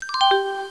chimes.au